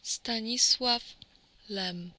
Stanisław Herman Lem (Polish: [staˈɲiswaf ˈlɛm]